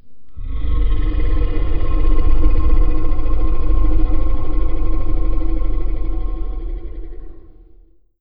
effect__car_reveal.wav